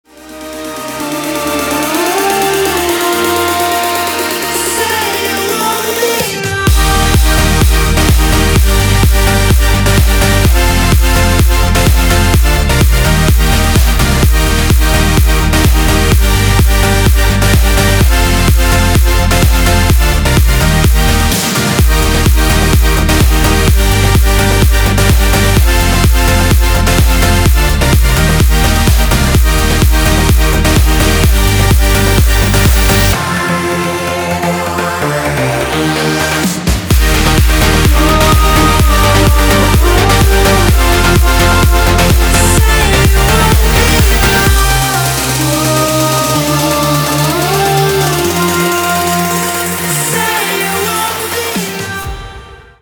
• Качество: 320, Stereo
ритмичные
громкие
мелодичные
заводные
dance
progressive house
electro
звонкие